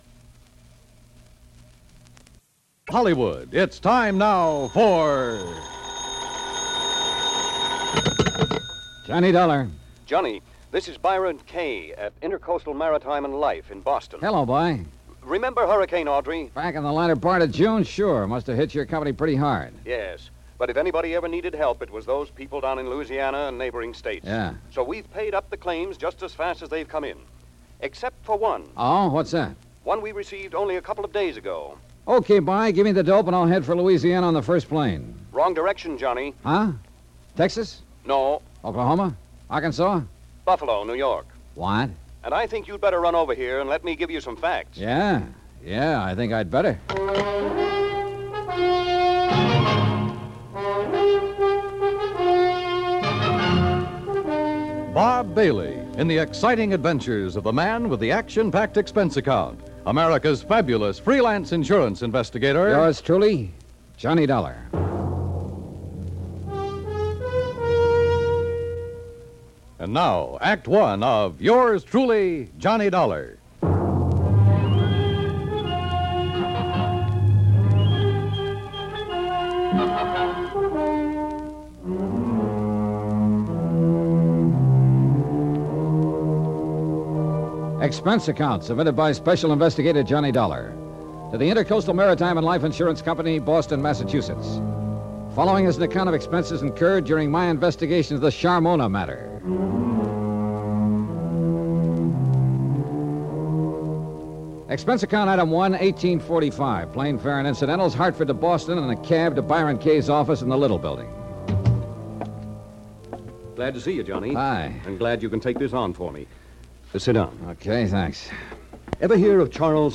Yours Truly, Johnny Dollar Radio Program, Starring Bob Bailey